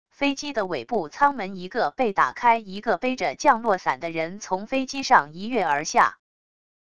飞机的尾部舱门一个被打开 一个背着降落伞的人 从飞机上一跃而下wav音频